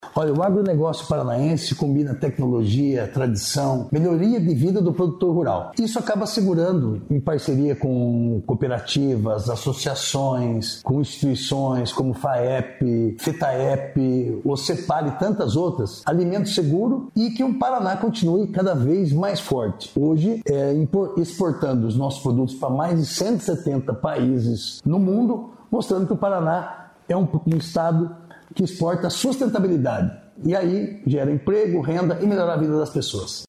Sonora do secretário da agricultura e abastecimento, Márcio Nunes, sobre o Congresso do Agronegócio Global